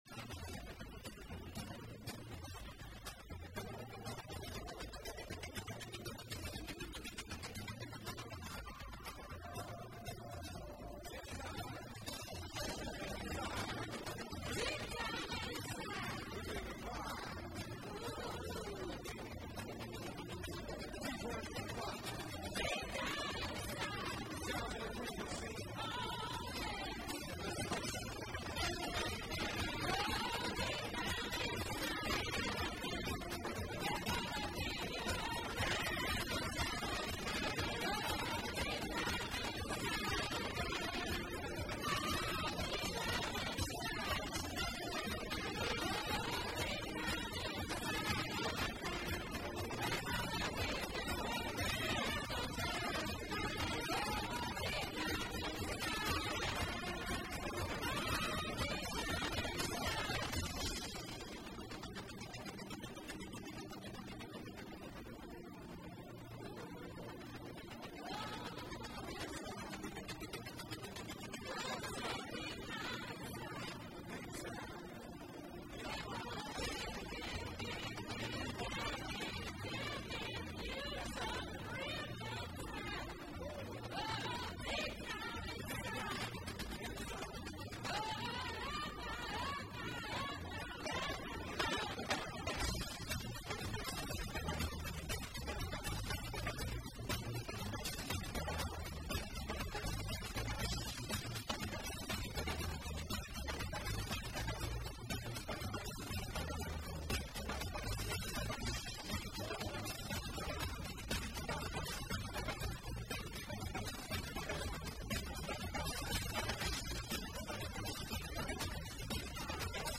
mashup of the adventuresome kind.